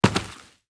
Dirt footsteps 7.wav